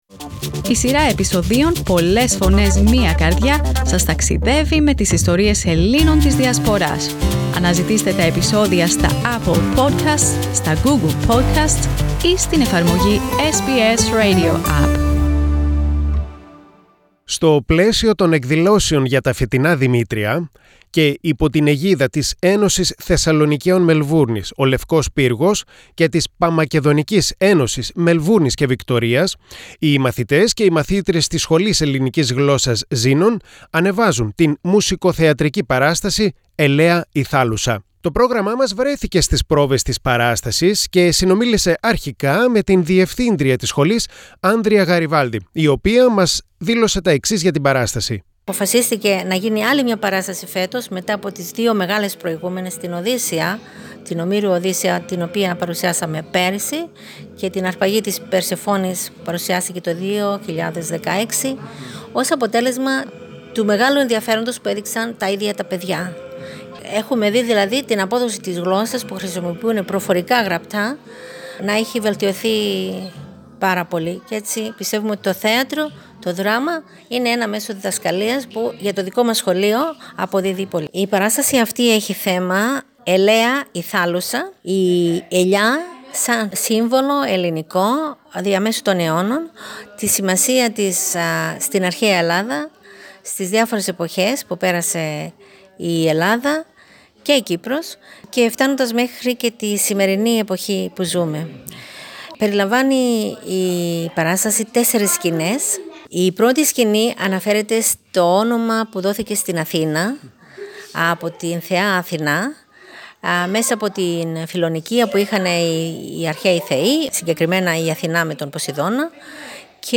Το SBS Greek βρέθηκε στις πρόβες της παράστασης.